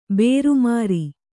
♪ bēru māri